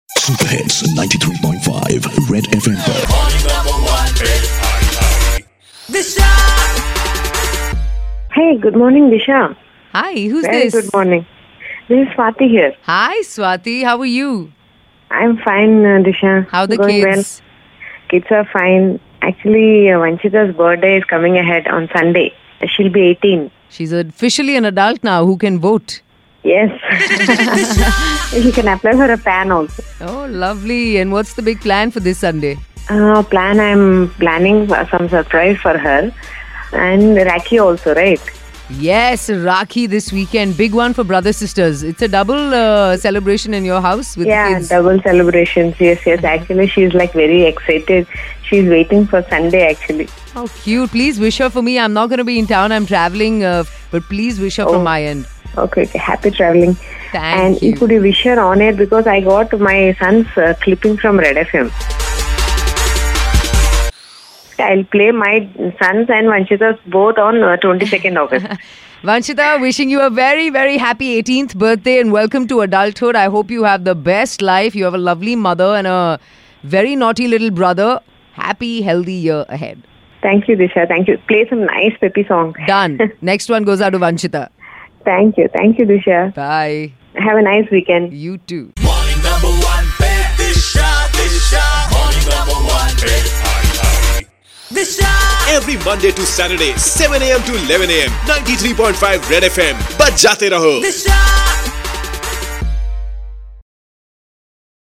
short, sweet conversation with her ardent listener